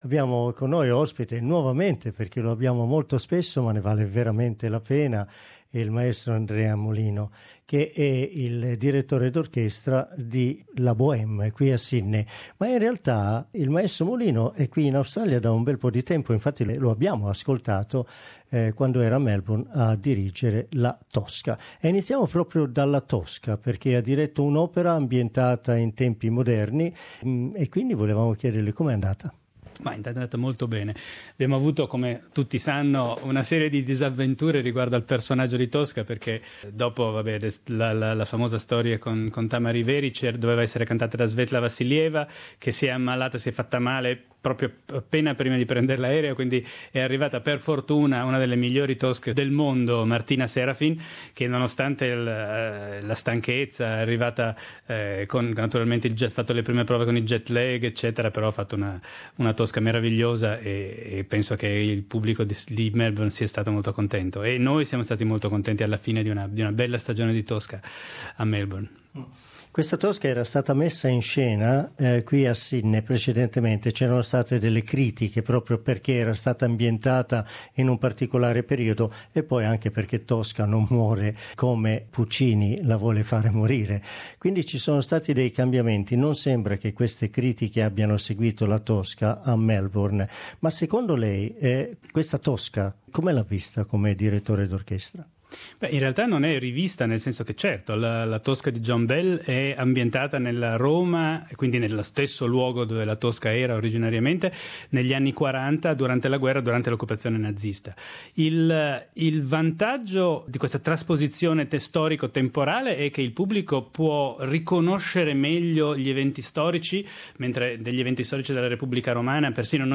Our interview